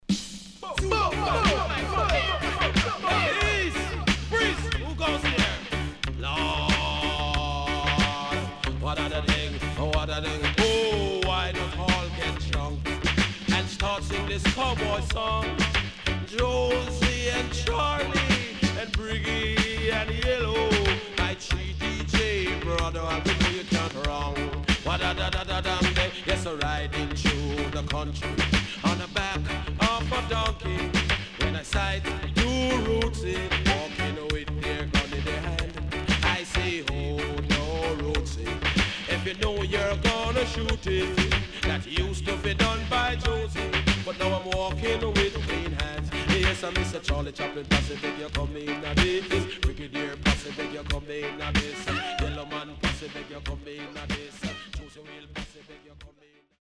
SIDE B:プレス起因により所々ノイズ入ります。